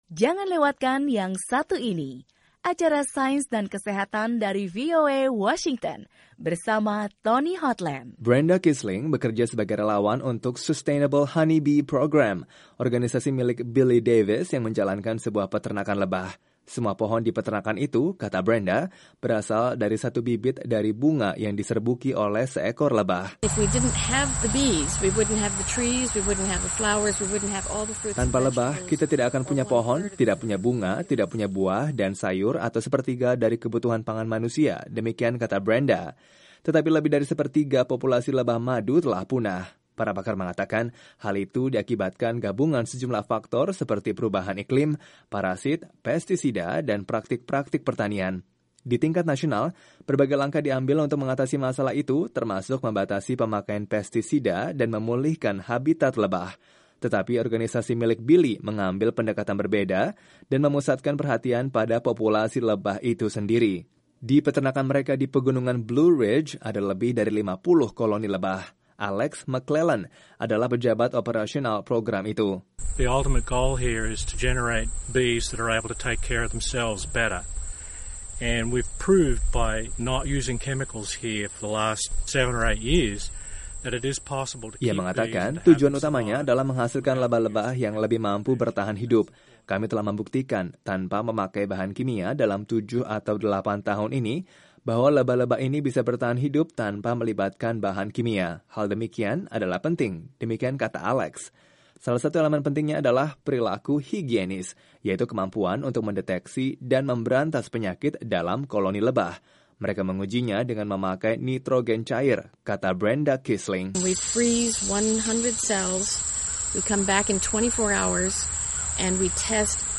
Populasi lebah madu terus menurun. Sebuah program nirlaba di Amerika berambisi untuk mengembangkan jenis lebah yang lebih produktif dan mengajar para petani memperkuat populasi lebahnya. Laporan Sains dan Kesehatan